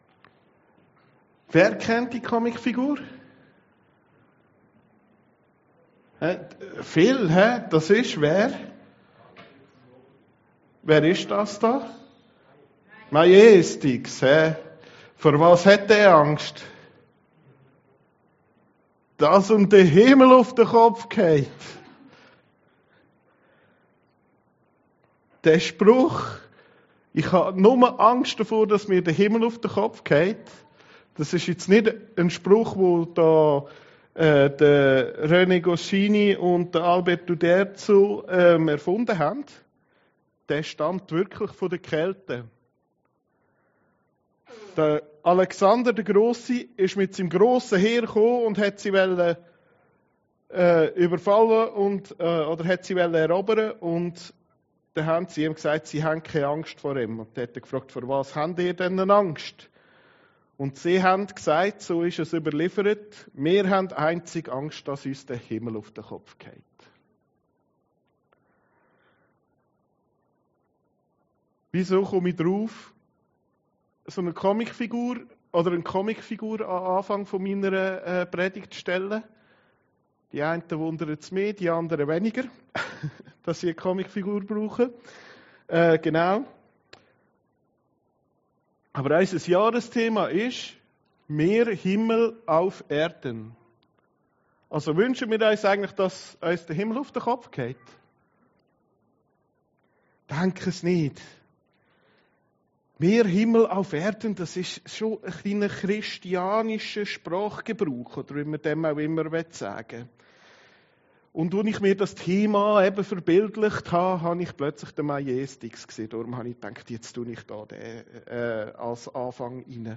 Predigten Heilsarmee Aargau Süd – Dein Reich komme